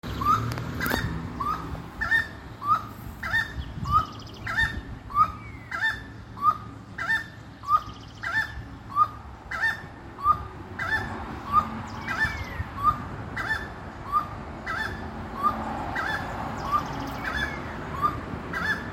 Chiricote (Aramides cajaneus)
Nombre en inglés: Grey-cowled Wood Rail
Localidad o área protegida: San Miguel, capital
Condición: Silvestre
Certeza: Vocalización Grabada